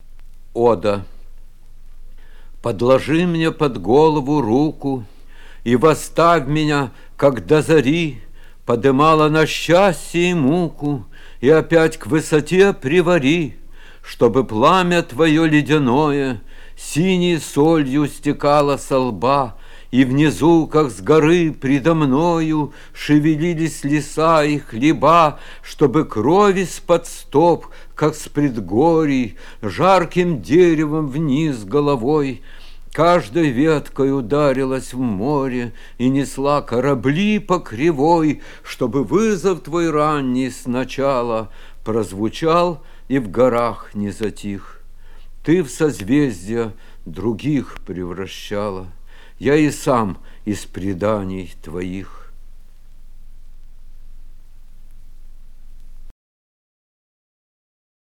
1. «Арсений Тарковский – Ода (читает автор)» /